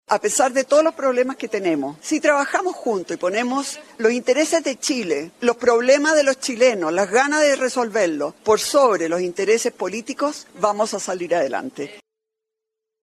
Sin mayores polémicas se desarrolló el panel presidencial en el Encuentro Nacional de la Empresa (Enade) 2025, en el que participaron siete de los ocho candidatos que buscan llegar a La Moneda.
Palabras finales de los candidatos
Evelyn Matthei ocupó sus últimas palabras para afirmar que, a pesar de los problemas, “Chile no está derrotado“.
211-cu-presidencial-evelyn-matthei.mp3